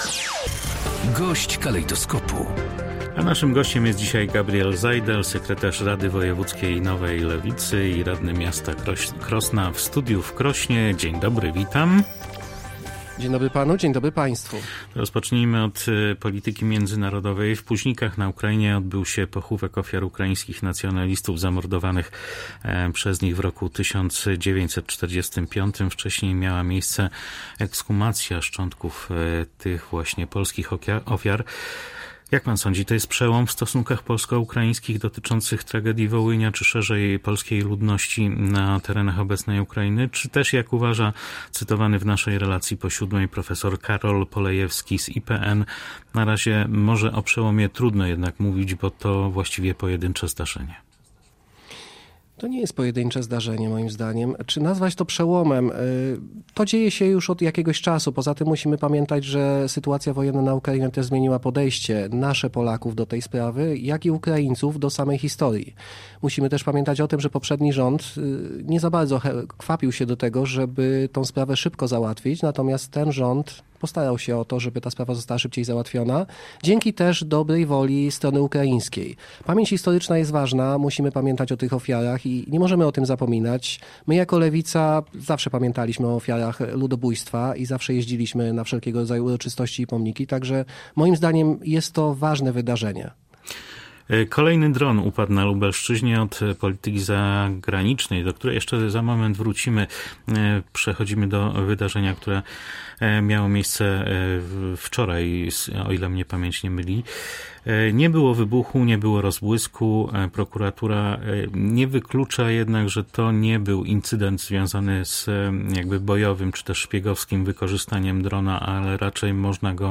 Więcej w rozmowie